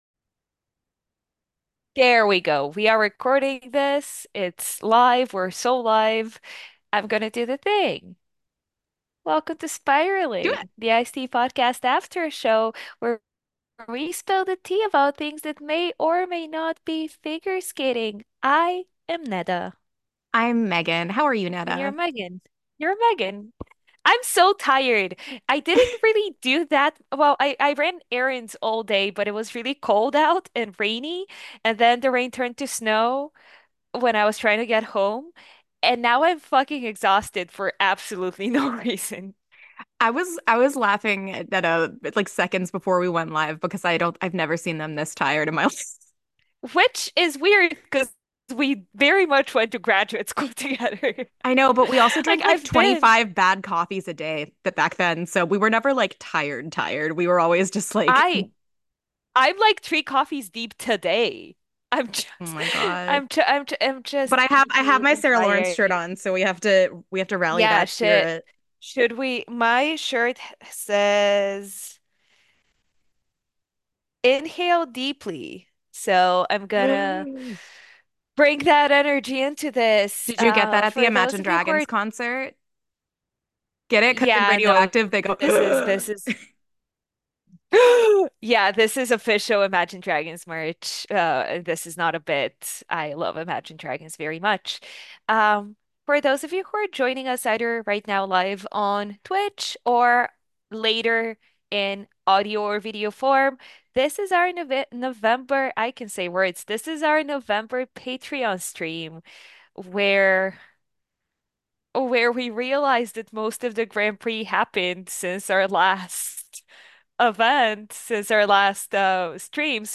Audio from the November 29th 2024 Bonus Livestream!